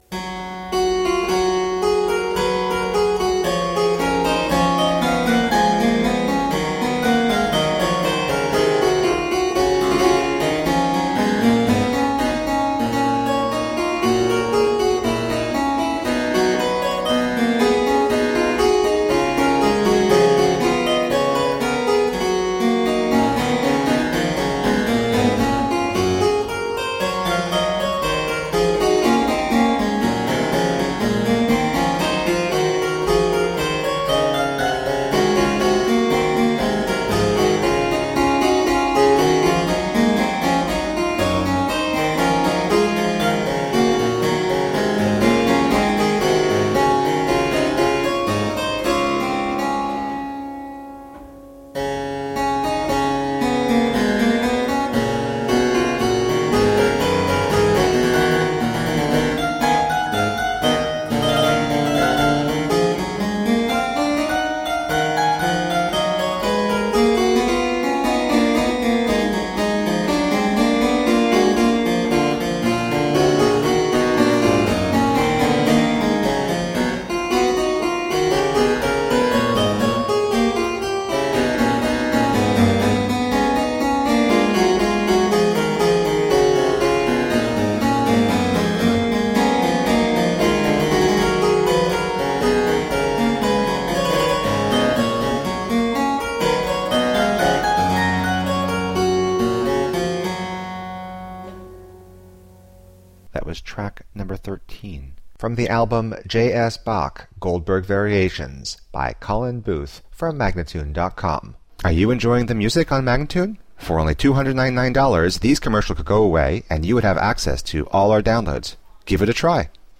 Solo harpsichord music.
Classical, Baroque, Instrumental, Harpsichord